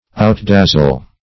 Outdazzle \Out*daz"zle\